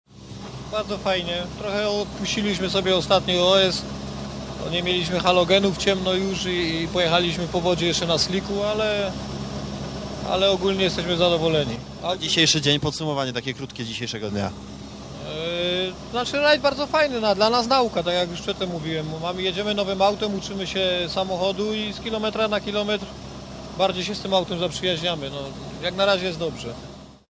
wypowiedź - 33 Rajd Elmot - serwis 3